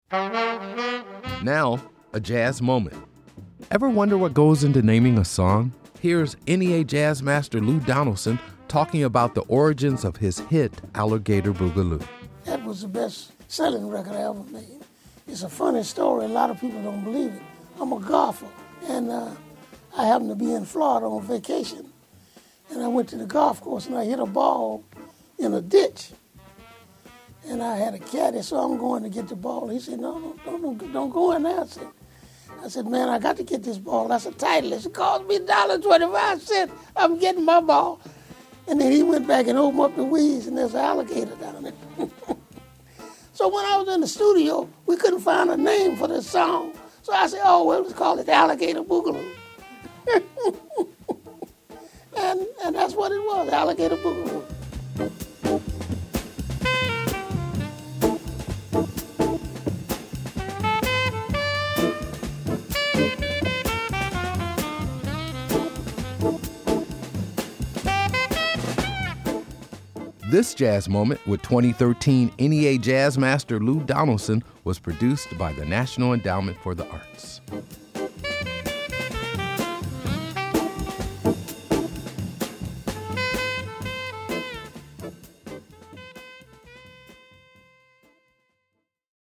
Excerpt of "Alligator Bogaloo" composed by Lou Donaldson from the album, Alligator Bogaloo, used courtesy of Blue Note / EMI Capitol and by permission of Sony ATV (BM